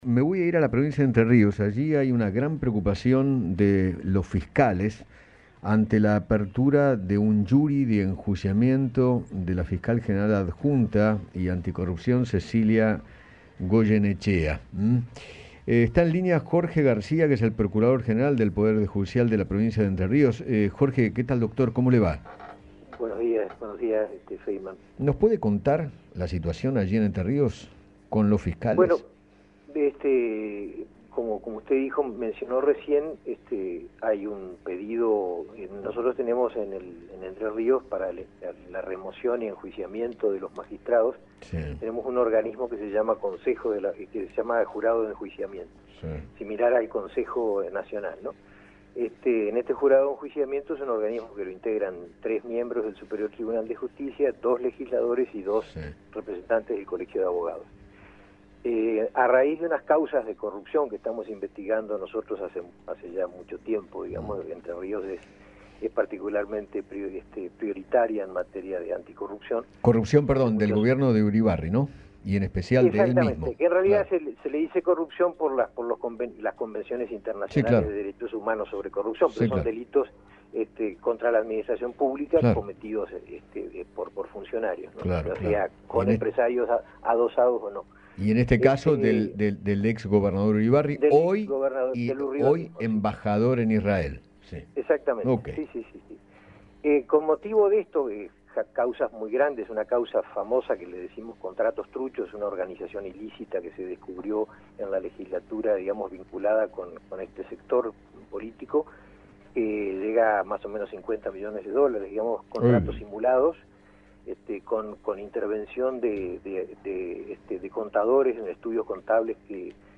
Jorge García, procurador general de Entre Rios, conversó con Eduardo Feinmann sobre la suspensión de la fiscal anticorrupción, Cecilia Goyeneche, quien coordinaba el cuerpo de fiscales que trabajaban en una investigación contra el ex gobernador Sergio Urribarri.